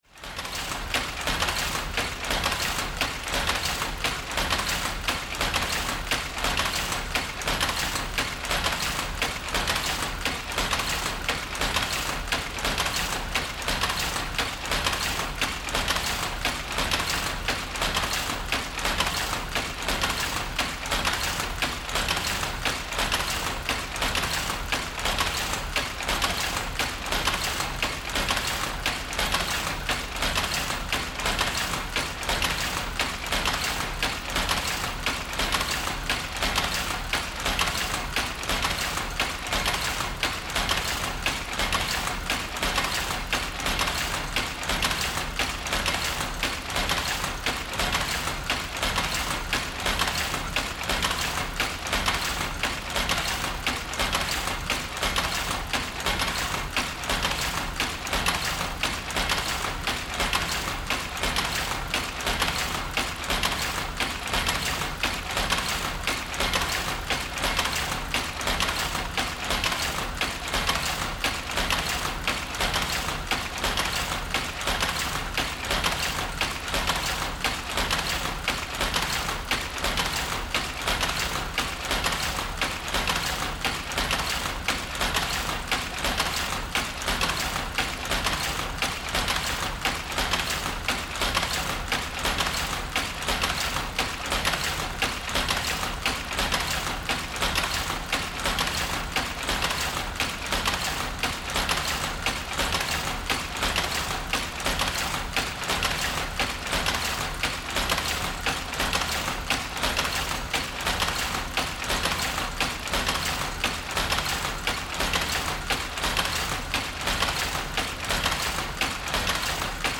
Half-width shuttle loom
The sound of loom in operation was recorded in the Central Museum of Textiles in Łódź (Poland).
It is a complete and fully operational power overpick loom intended for the manufacturing of bareface fabric. It was manufactured in 1889 in Fred Greenwood Mechanical Works in Łódź. Initially, the loom had been equipped with joint line shaft, replaced in the 20th century by electric motor.